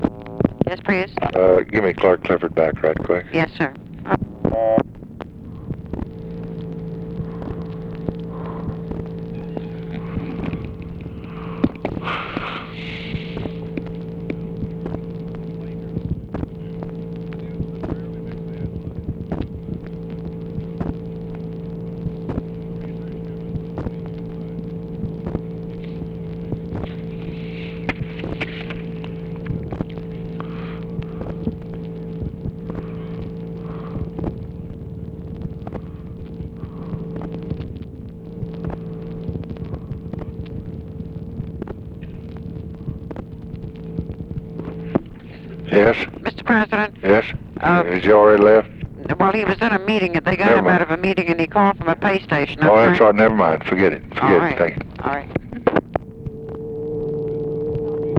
LBJ ASKS OPERATOR TO PLACE CALL TO CLARK CLIFFORD AGAIN BUT HE IS UNAVAILABLE
Conversation with TELEPHONE OPERATOR
Secret White House Tapes | Lyndon B. Johnson Presidency